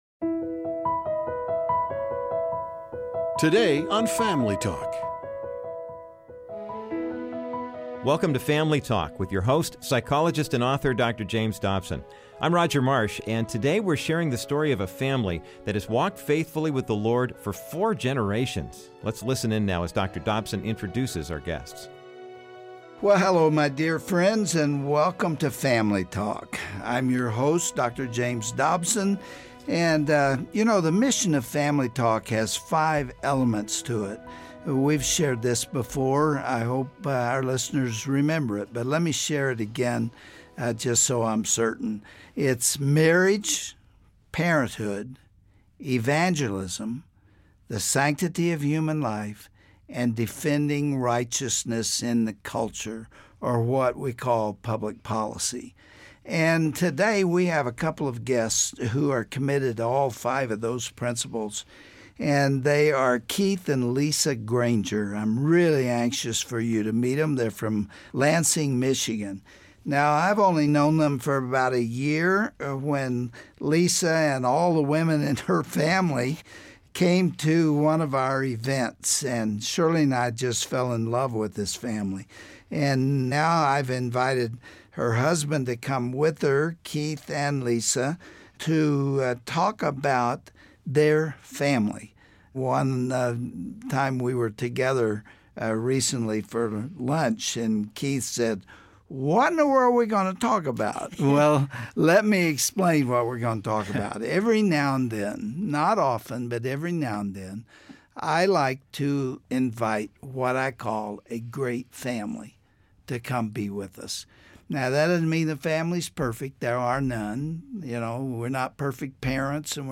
They joined Dr. Dobson in the studio to talk about creating a Christian legacy at home and in the office.